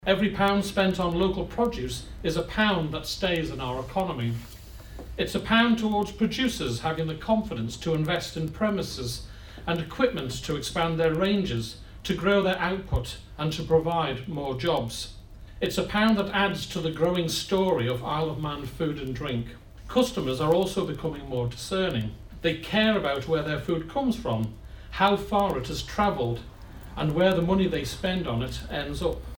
Tourism businesses are being encouraged to stock the publication so visitors are aware of the Island's offering - Chief Minister Howard Quayle says it's important for the economy: